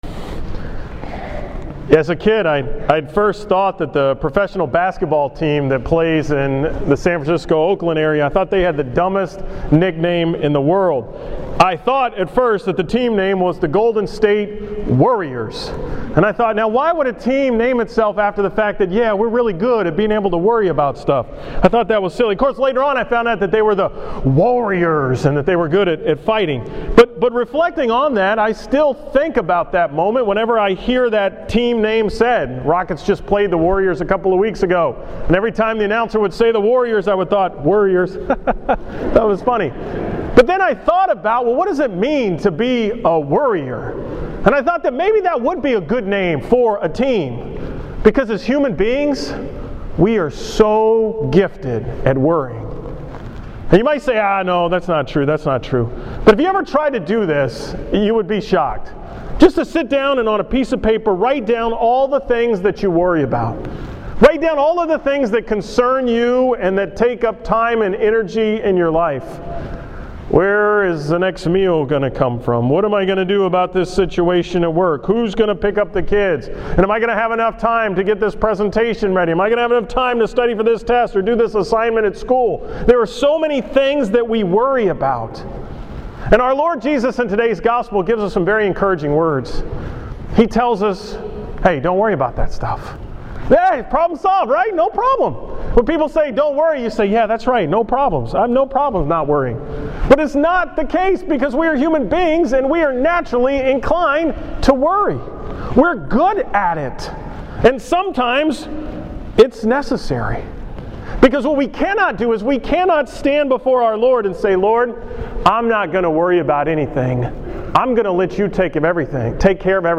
Homily on the 8th Sunday of Ordinary Time